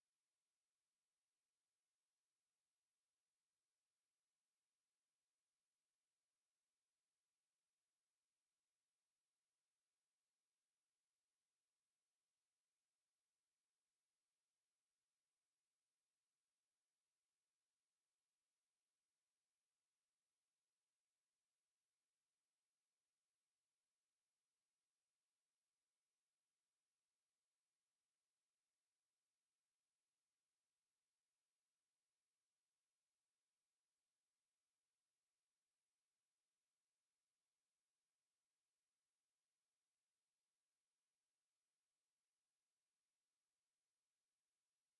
Weihnachtsmusical
Viele Lieder in kunterbunter Mischung prägen das Musical.